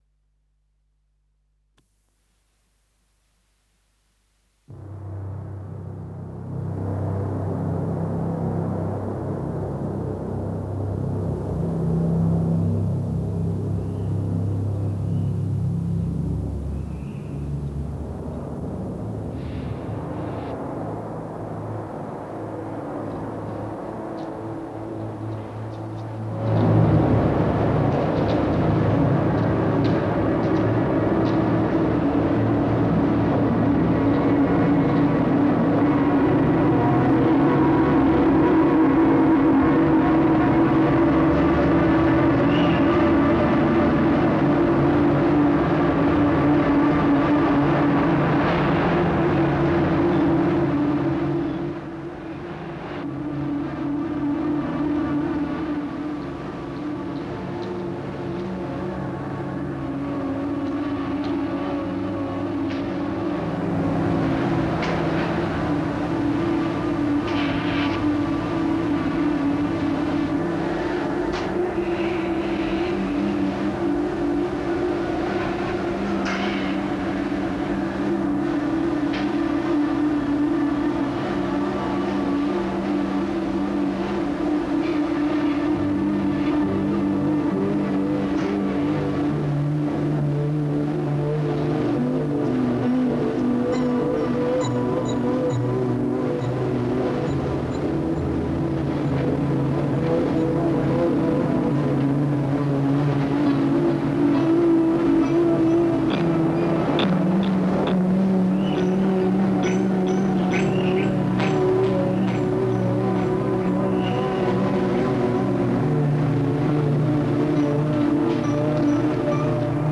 Ηχητική εγκατάσταση
Μπομπινόφωνο
Μικρόφωνα επαφής
Κόκκοι θορύβου που κατακάθονται πάνω στις κενές επιφάνειες. Ο υπόκωφος βόμβος, η ανάσα του αστικού τοπίου, διαπερνά τοίχους, αντικείμενα και σώματα, μεταλλάσεται με κάθε εμπόδιο που συναντά, αλλά δεν φτάνει ποτέ στο σημείο της σιωπής.